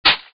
Chainwav.mp3